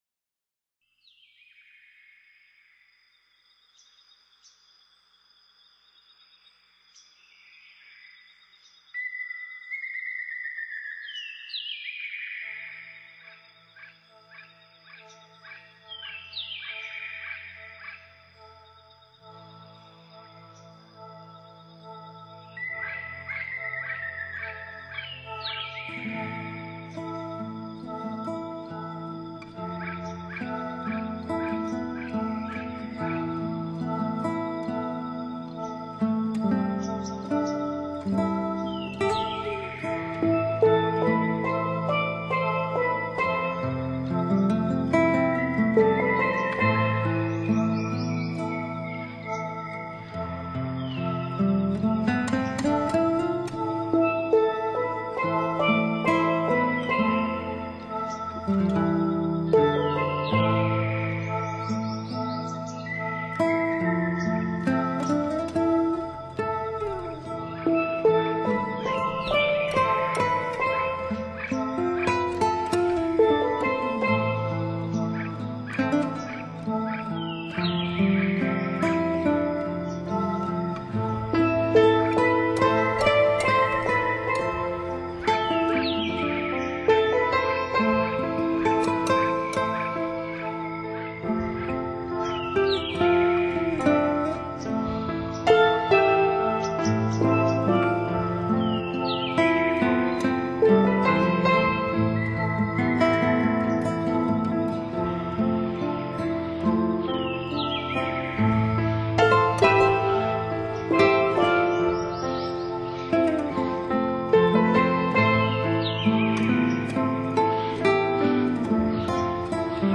类别：自然音乐
，运用了先进的录音技术，把大海中孤岛上所能听到的自然之声和人类的纯净音乐，辅以天使般的女声吟唱，产生了非常动人的效果。